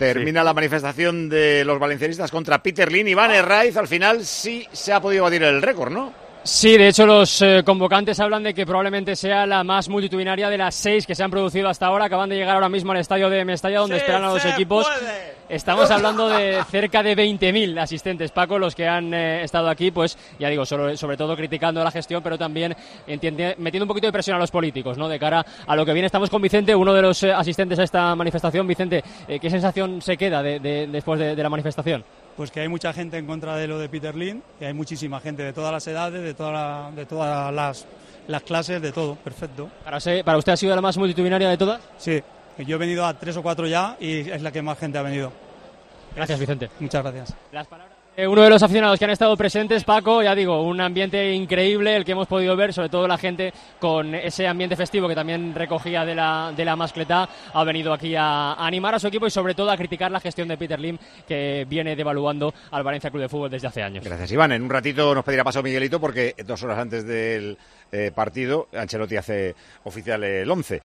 Manifestación contra la gestión de Peter Lim